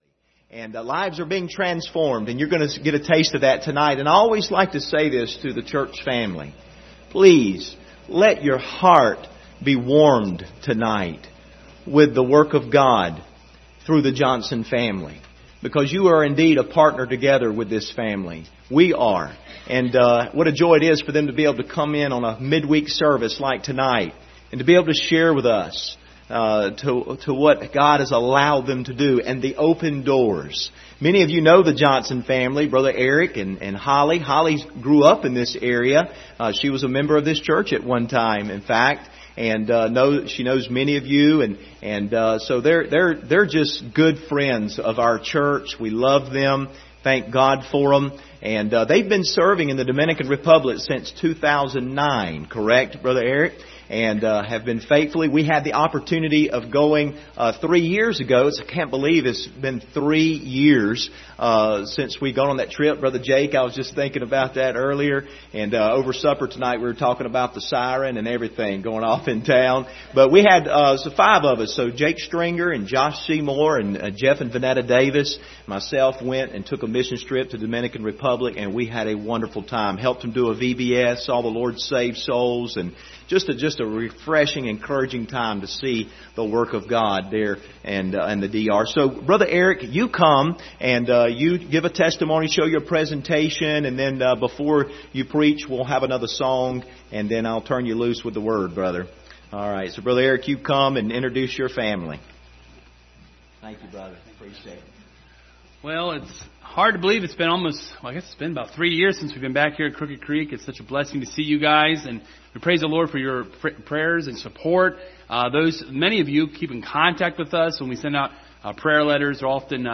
Passage: Romans 10:13-15 Service Type: Wednesday Evening Topics